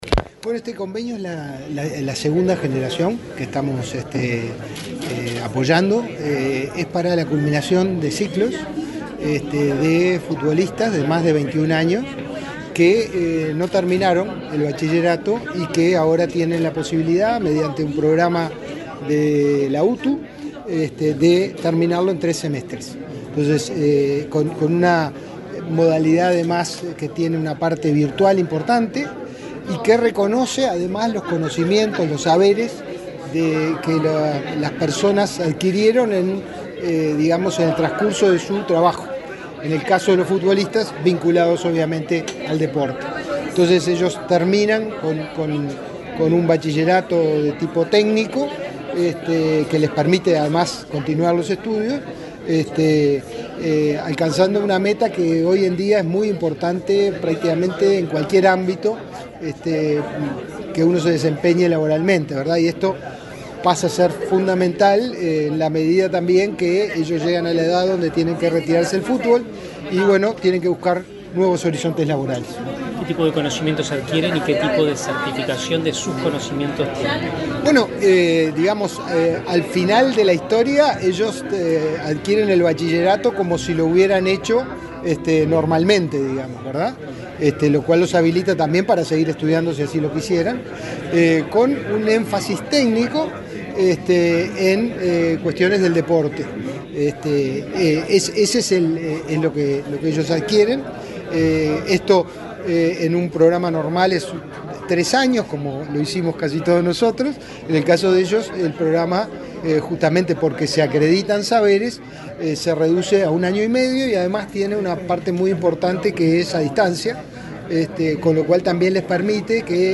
Declaraciones del director de Inefop, Pablo Darscht
El director del Instituto Nacional de Empleo y Formación Profesional (Inefop), Pablo Darscht, dialogó con la prensa, luego de firmar un acuerdo con el